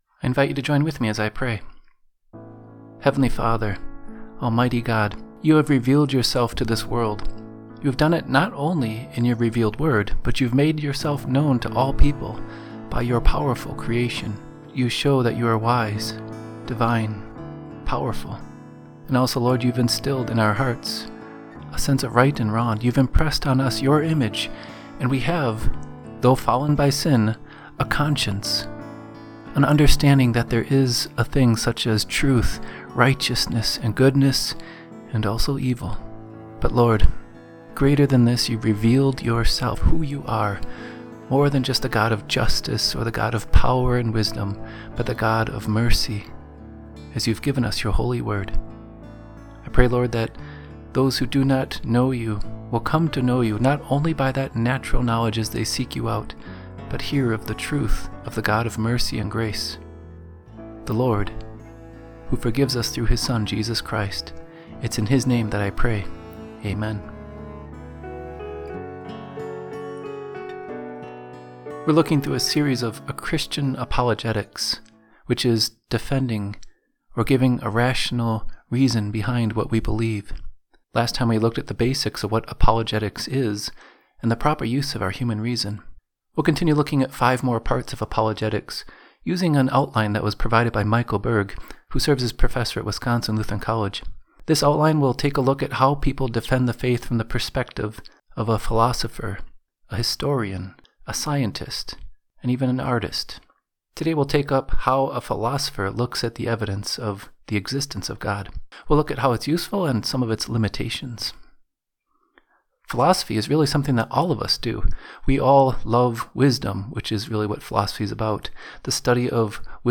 Listen to a brief devotion based on part 2 of our Apologetics study.